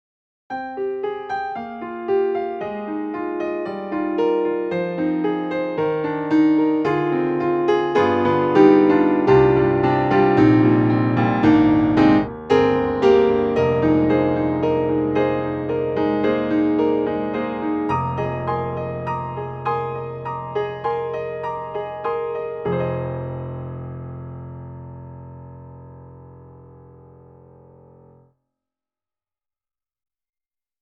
All Mic Close/Player Full Side/Room Half
VI Labs_Ravenscroft 275_6_All Mic Close and Player Full Side and Room Half.mp3